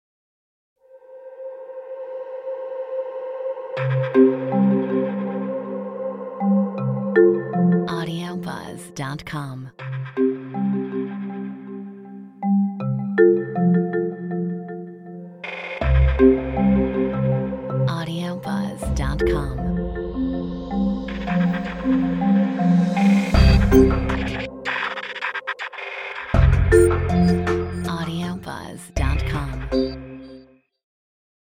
Metronome 160
Marimba instrumental music
Quirky, positive, fun and light.